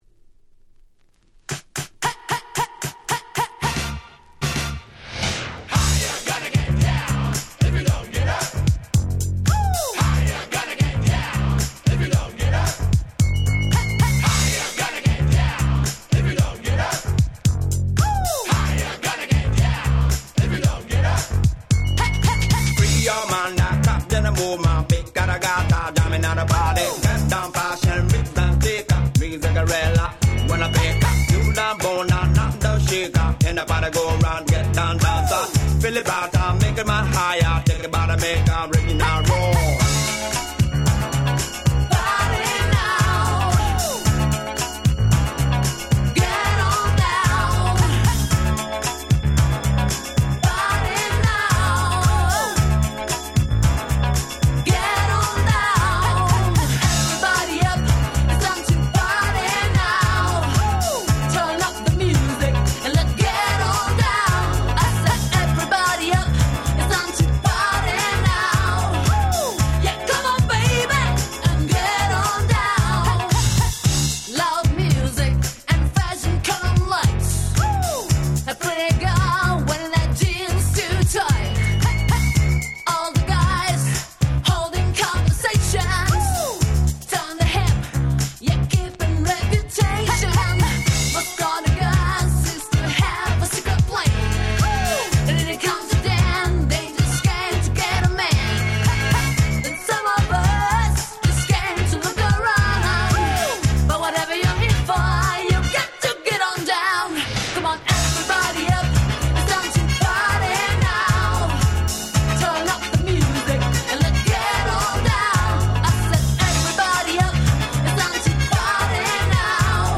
95' Super キャッチーR&B !!